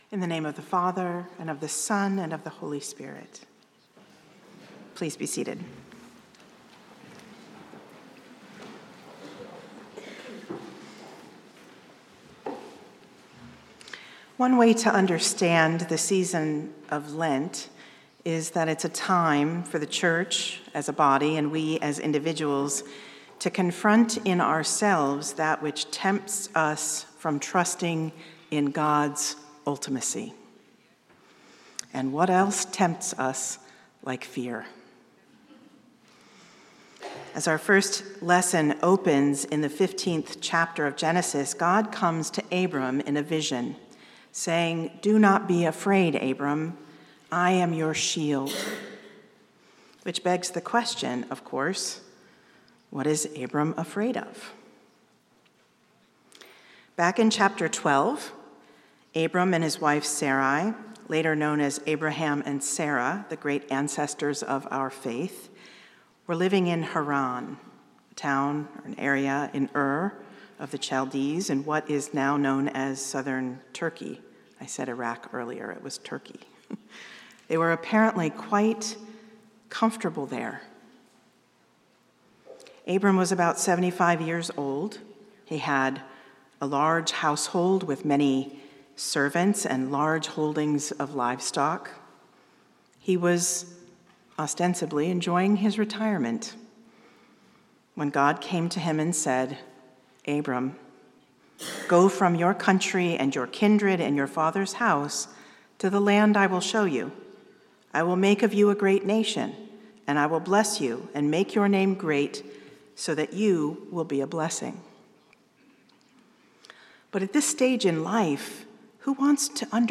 St-Pauls-HEII-9a-Homily-16MAR25.mp3